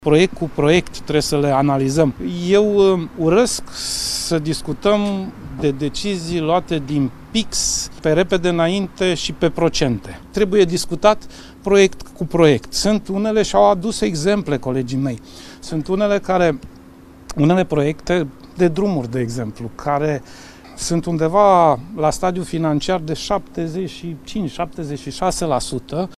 Trebuie analizat proiect cu proiect, spune președintele interimar al PSD, Sorin Grindeanu